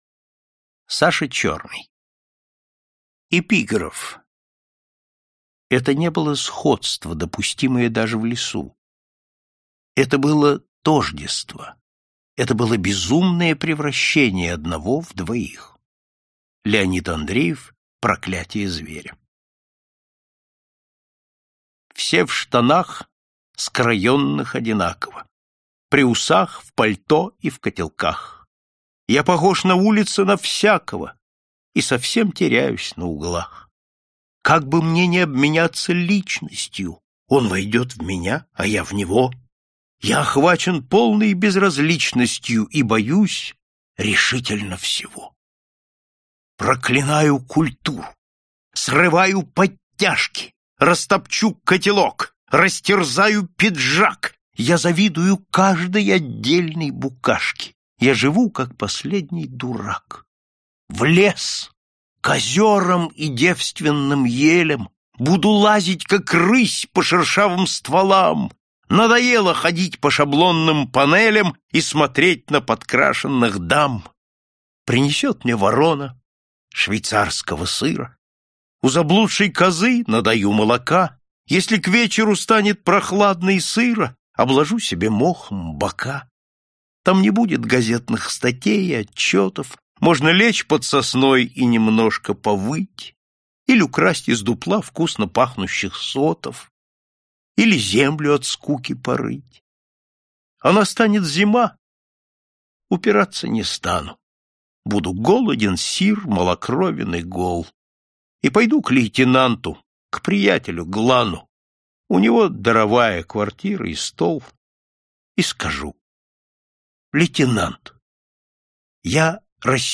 Аудиокнига Классика русского юмористического рассказа № 4 | Библиотека аудиокниг